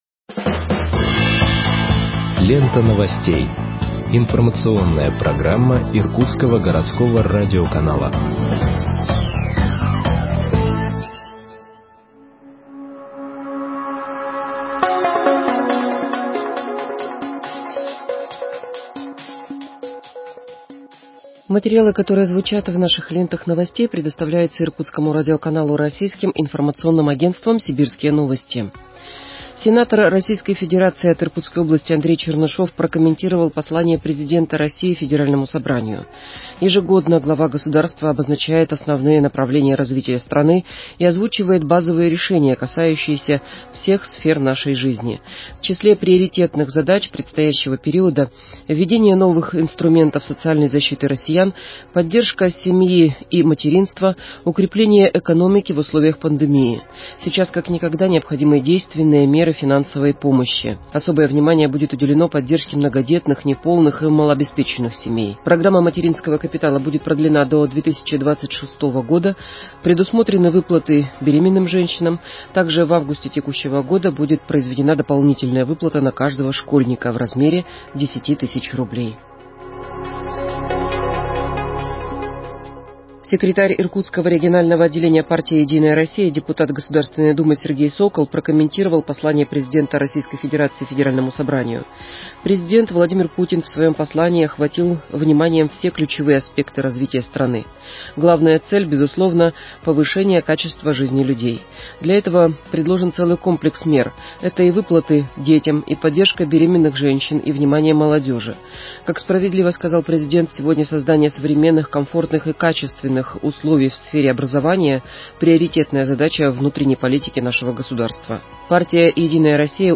Выпуск новостей в подкастах газеты Иркутск от 22.04.2021 № 2